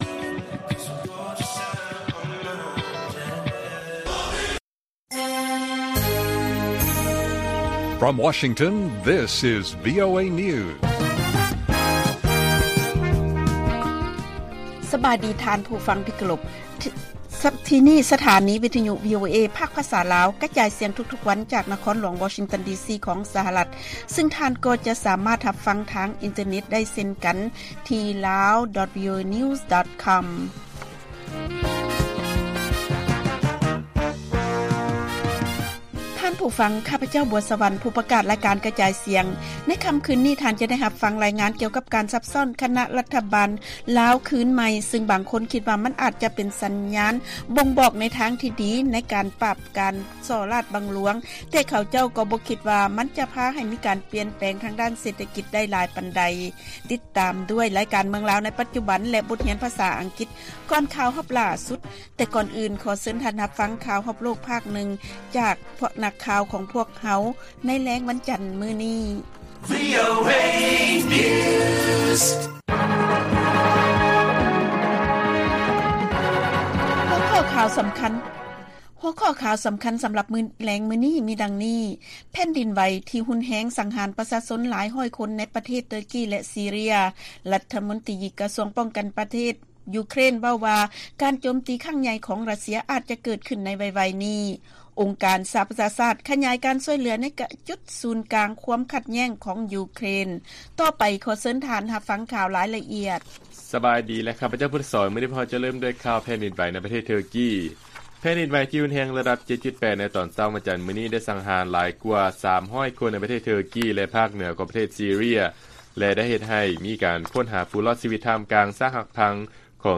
ລາຍການກະຈາຍສຽງຂອງວີໂອເອ ລາວ: ແຜ່ນດິນໄຫວທີ່ຮຸນແຮງ ສັງຫານປະຊາຊົນຫຼາຍຮ້ອຍຄົນ ໃນປະເທດ ເທີກີ ແລະ ຊີເຣຍ